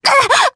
Viska-Vox_Damage_jp_02.wav